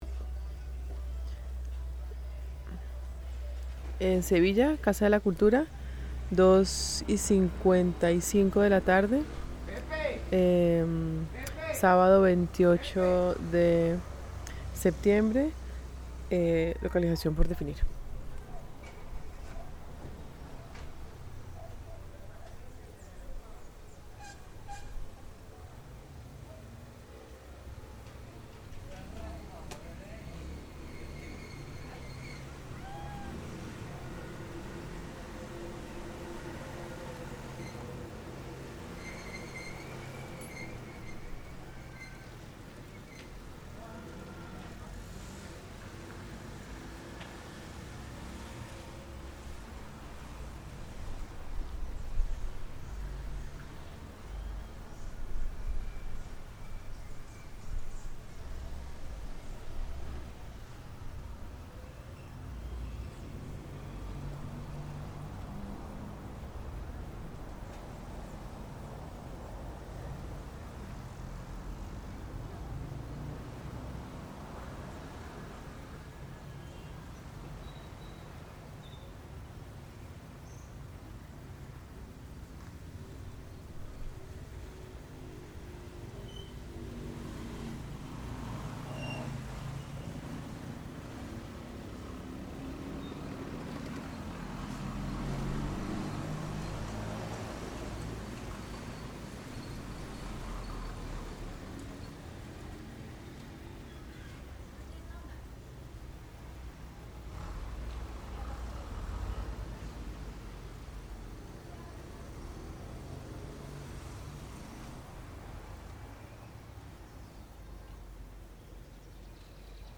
Audio CasaCultura_Sevilla.mp3 Resumen Descripción Paisaje sonoro de calle por la Casa de la Cultura en Sevilla, Valle del Cauca.